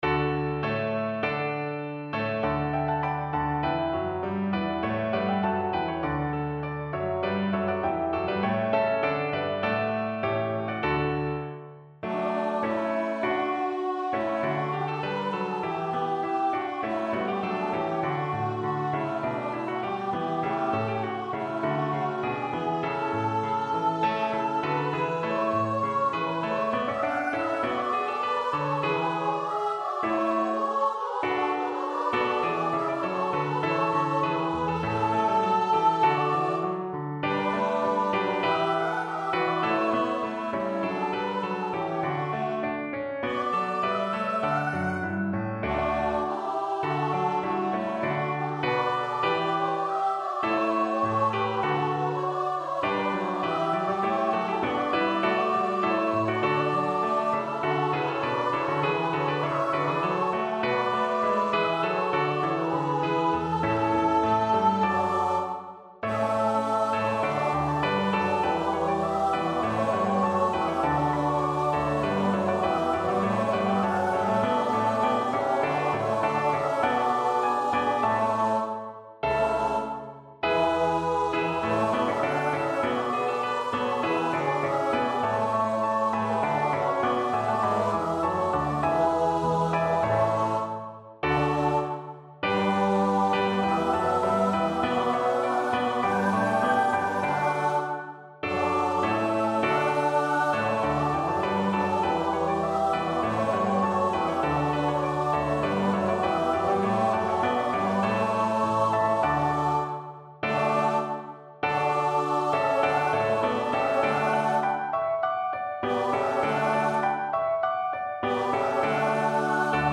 Hail, Judea, happy land (Judas Maccabaeus) Choir version
Choir  (View more Intermediate Choir Music)
Classical (View more Classical Choir Music)